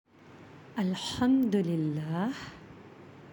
How To Say Alhumdulillah Tutorial